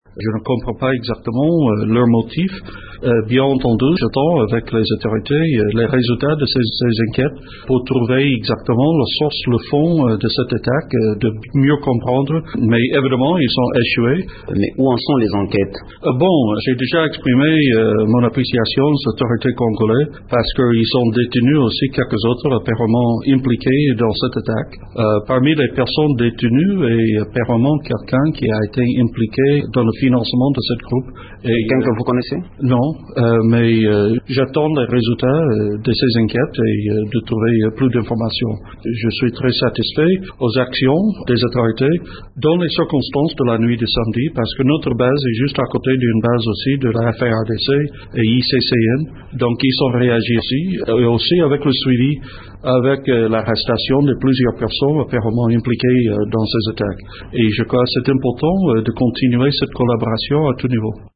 Le représentant spécial du secrétaire général de l’Onu s’est exprimé pour la première fois jeudi 28 août  dans un point de presse tenu à son cabinet à  Kinshasa , sur l’attaque d’une base des casques bleus samedi dernier à Rwindi, au Nord Kivu.